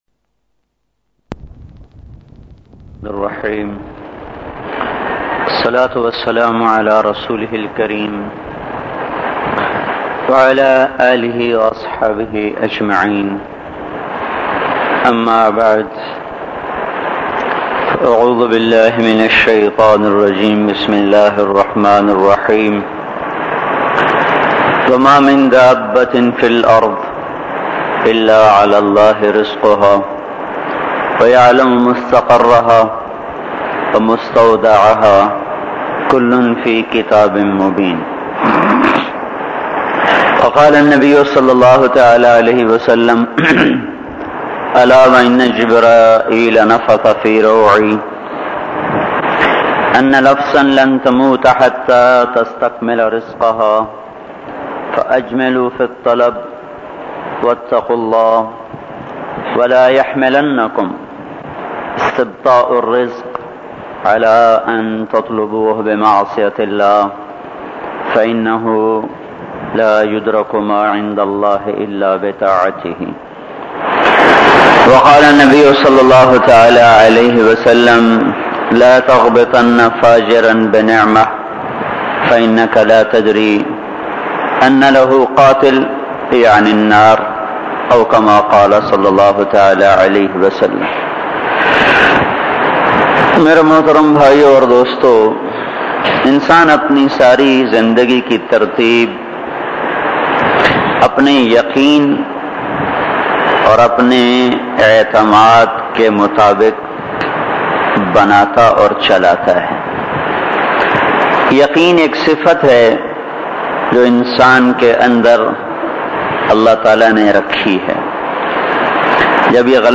JANAT KI MAHMANI bayan MP3